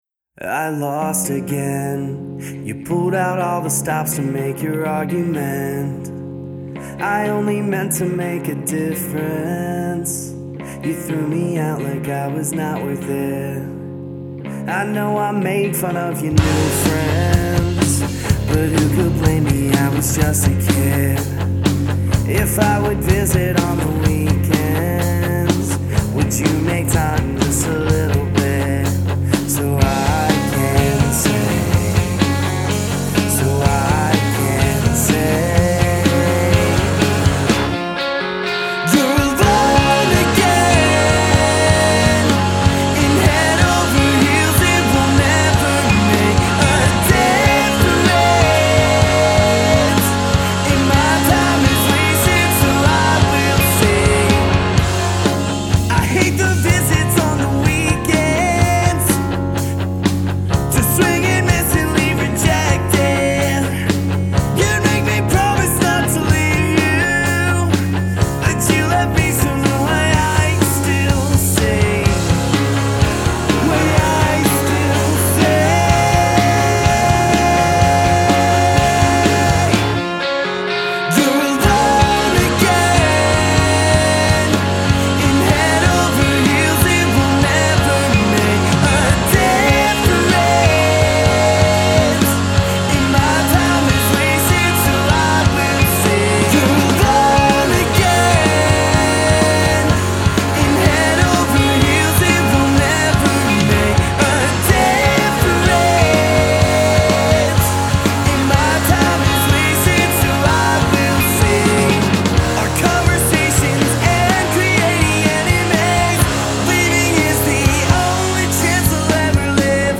local pop-punk outfit
catchy power ballad